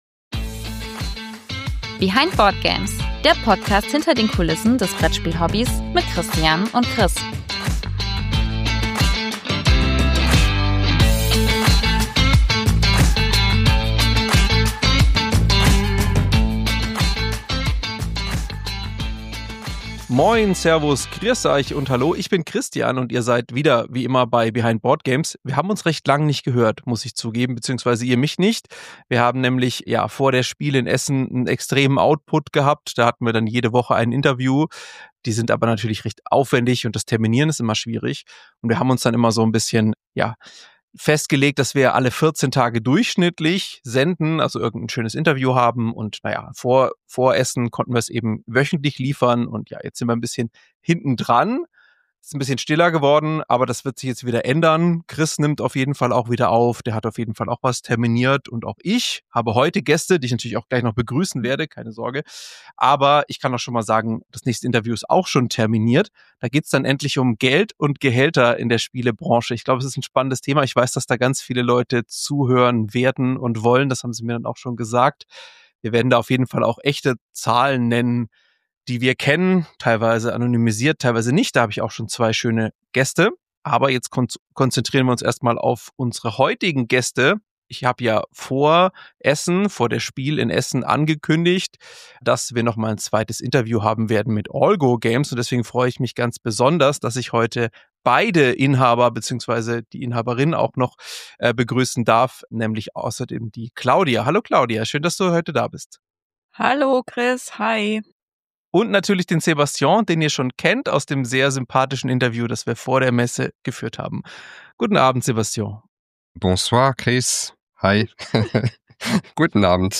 Wenn ihr erfahren wollt, ob alle Spiele rechtzeitig zur Messe gekommen sind, welche unerwarteten Hürden aufgetaucht sind und wie stabil die Messeregale waren, hört sich gerne das zweite Interview an.